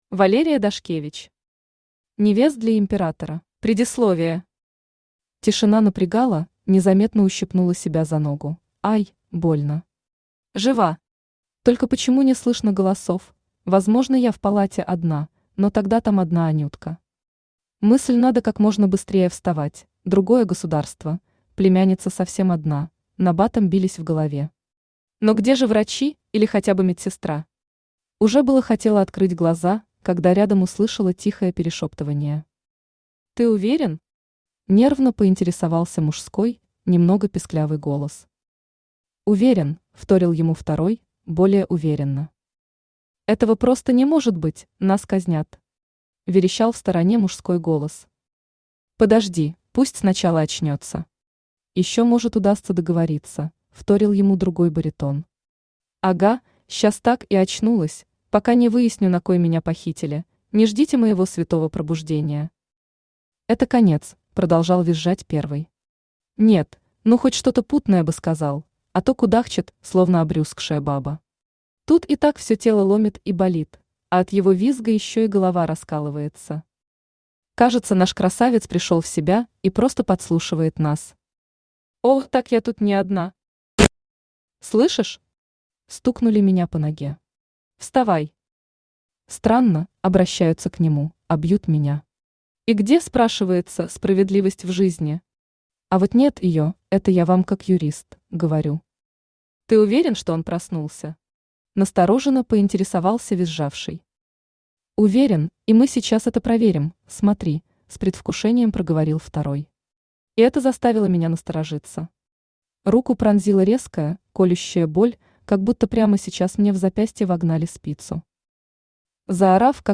Аудиокнига Невест для Емператора | Библиотека аудиокниг
Aудиокнига Невест для Емператора Автор Валерия Дашкевич Читает аудиокнигу Авточтец ЛитРес.